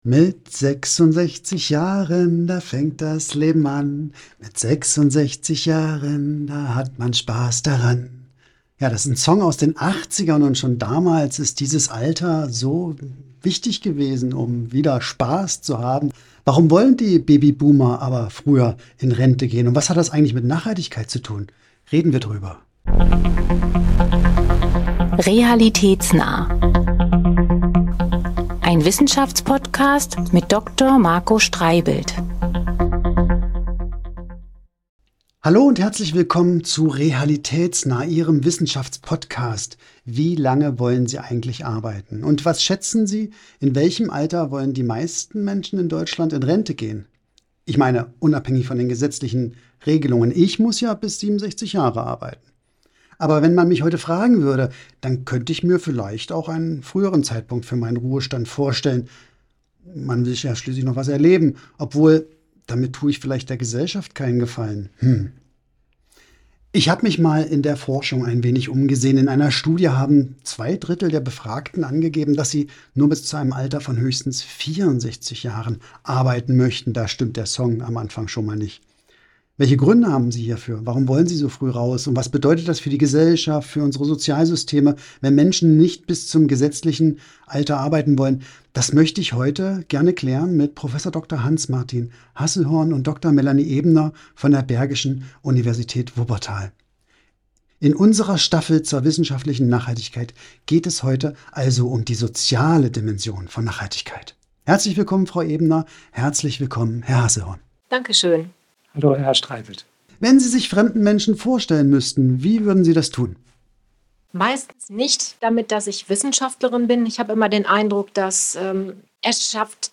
Disclaimer: In dieser Folge wird gesungen.